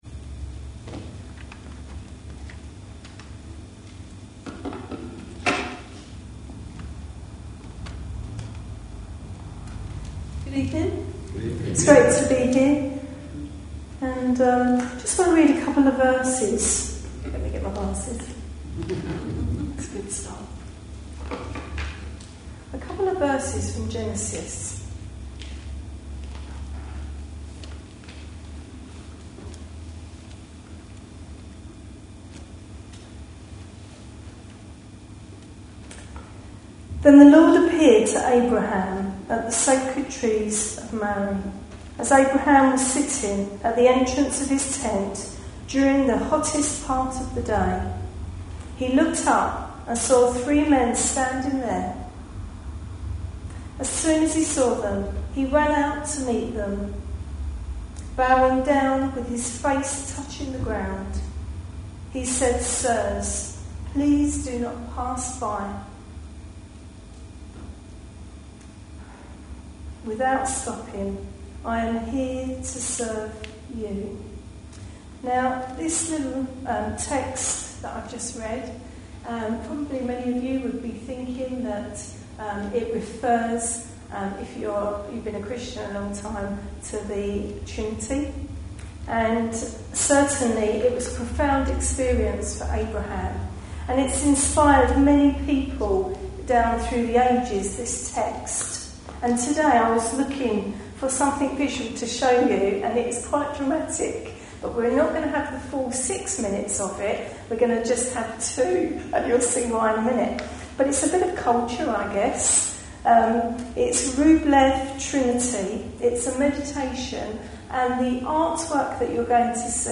A sermon preached on 3rd June, 2012, as part of our The Trinity in Action series.